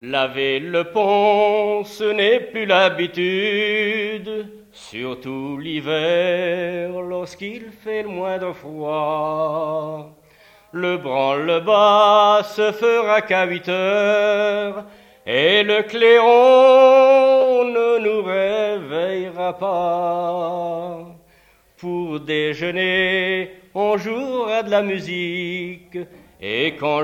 Mémoires et Patrimoines vivants - RaddO est une base de données d'archives iconographiques et sonores.
Genre strophique
Témoignages sur la pêche, accordéon, et chansons traditionnelles
Pièce musicale inédite